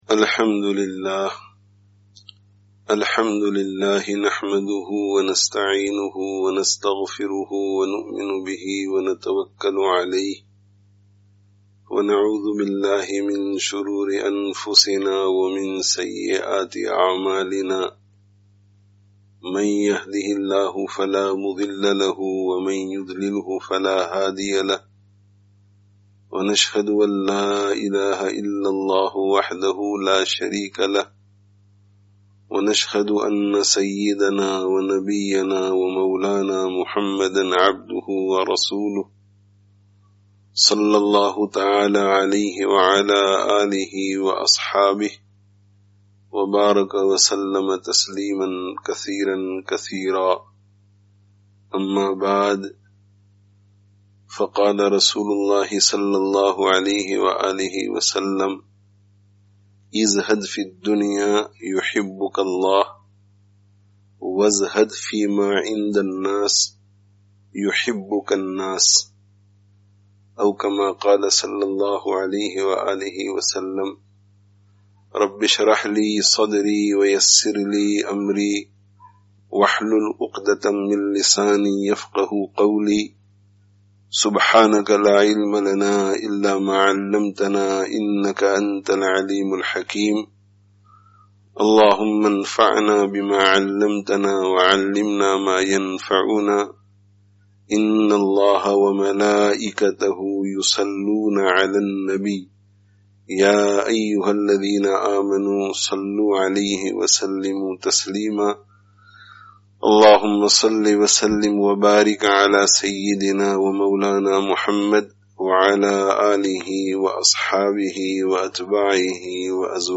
Download friday tazkiyah gathering Urdu 2020 Related articles Allāh ta'ālā kī Farmā(n)bardārī me(n) Kāmyābī hī Kāmyābī hai (14/08/20) Be Shumār Ni'mato(n) ke Bāwajūd Mahrūmī kā Ihsās?